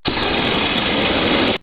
Added new spray sound effects and updated/changed those that were used before for fire extinguisher, refueling/refilling, using cleaner and using weed-b-gone.
extinguish.ogg